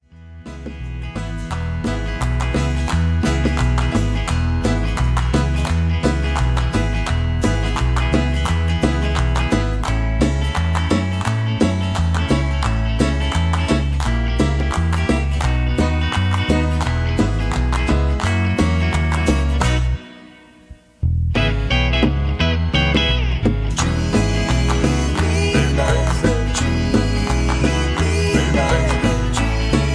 karaoke, mp3 backing tracks
easy litstening, country rock, country music, backing tracks